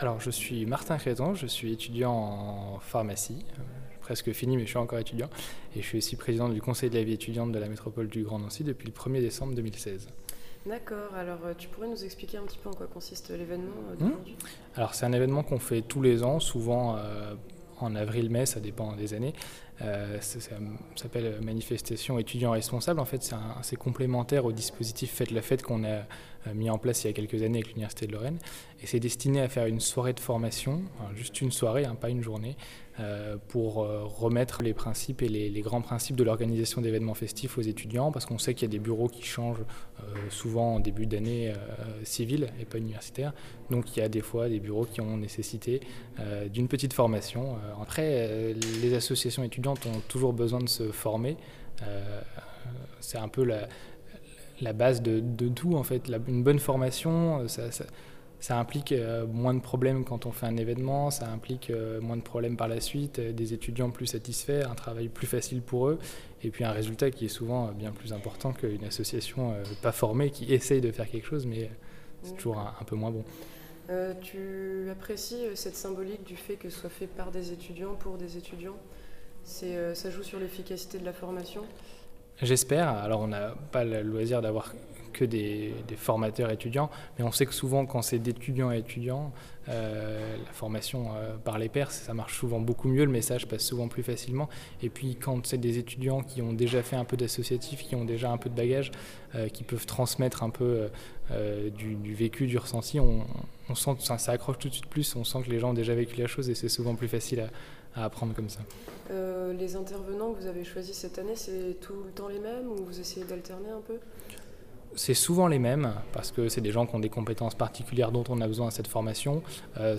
interview-crton.mp3